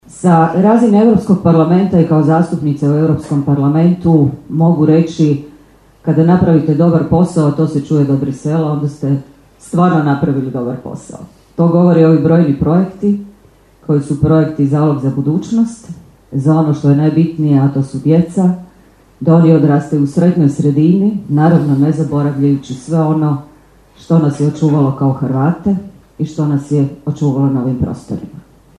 Svečana sjednica Gradskog vijeća Pakraca upriličena je u povodu Dana Grada Pakraca u Hrvatskom domu dr. Franjo Tuđman u Pakracu.
Obraćajući se prisutnima zastupnica u EU parlamentu Sunčana Glavak, rekla je kako se za dobre projekte dobar glas daleko se čuje